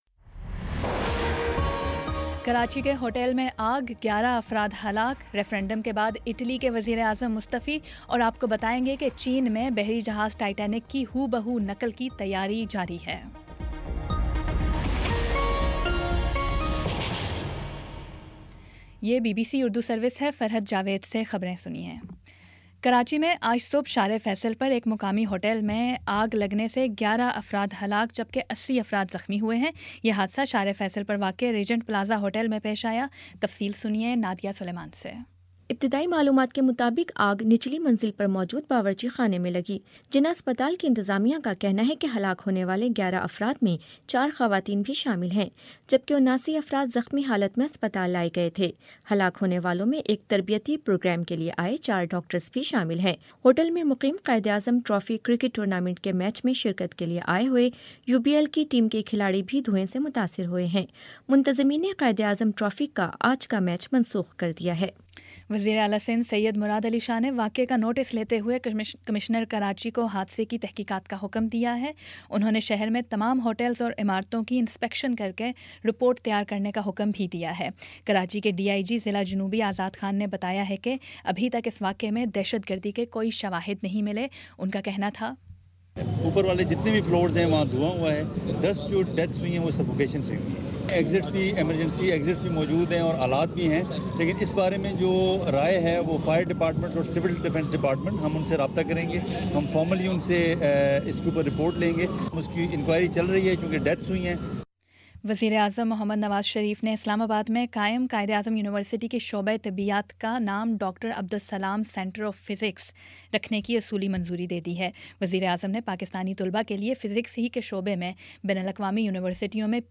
دسمبر 05 : شام پانچ بجے کا نیوز بُلیٹن